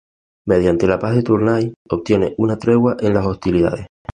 tre‧gua
Pronúnciase como (IPA)
/ˈtɾeɡwa/